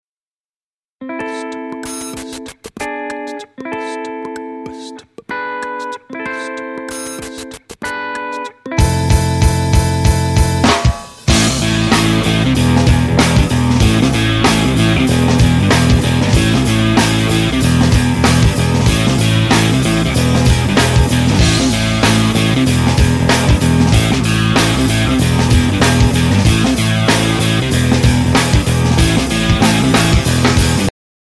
• Качество: 128, Stereo
без слов
инструментальные